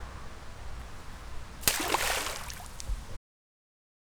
os sons do eco-trilho
Lago-som-de-pedra-sobre-o-a-agua.wav